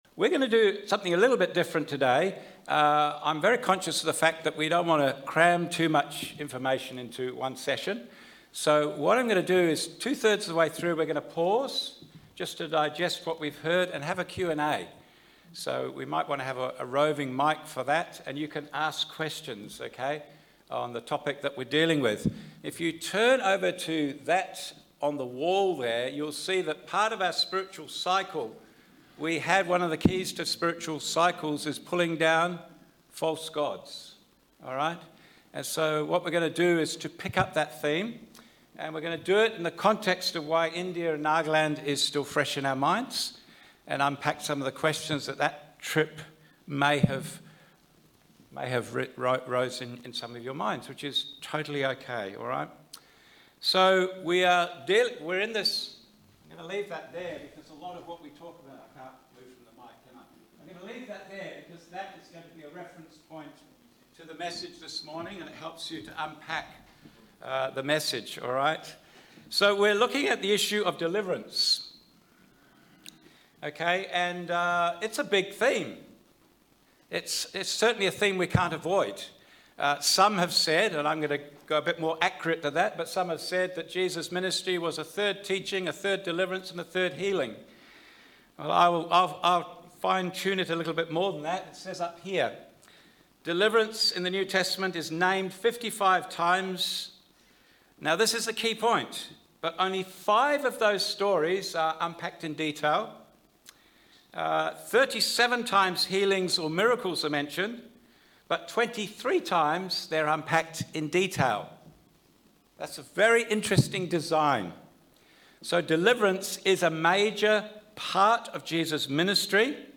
SermonSUN2602.mp3